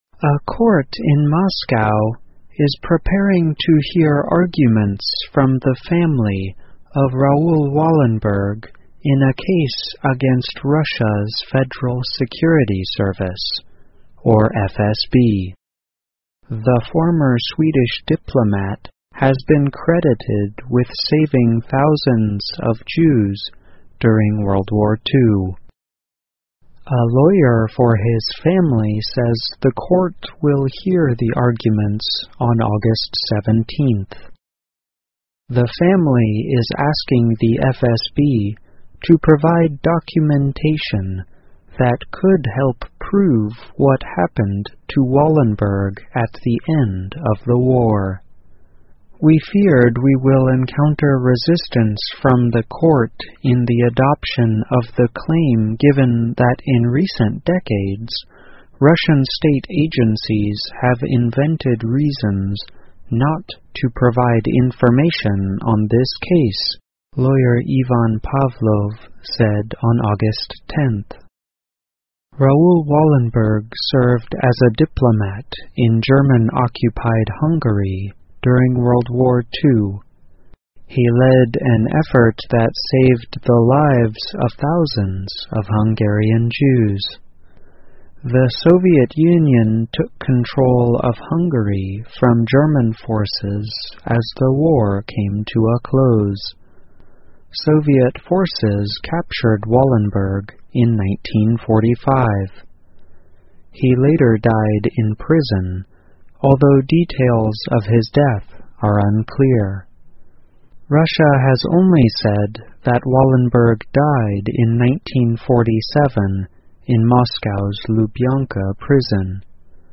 VOA慢速英语--俄罗斯法院听取瓦伦贝格案 听力文件下载—在线英语听力室